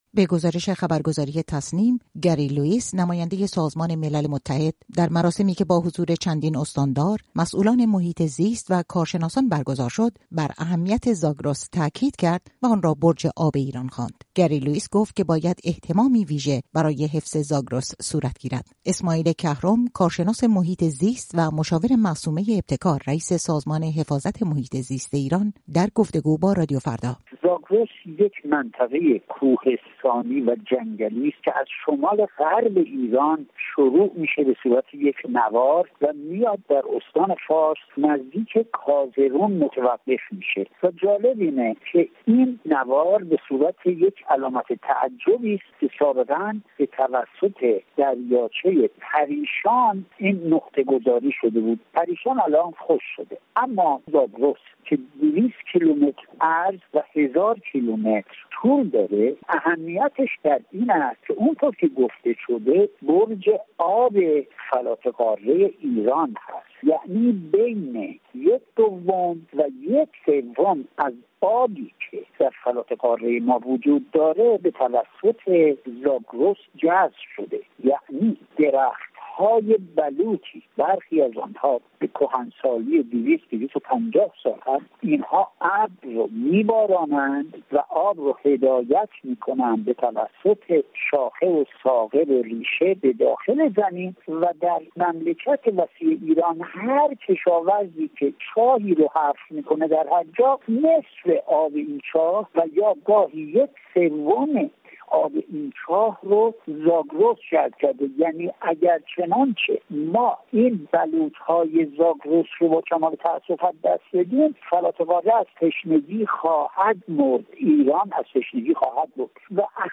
گفتگو با یک کارشناس محیط زیست و یک اقلیم شناس.